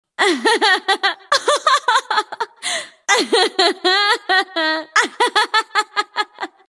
Risada Irelia (LoL)
Risada da personagem Irelia, a Dançarina das Lâminas, em League Of Legends (LoL).
risada-irelia-lol.mp3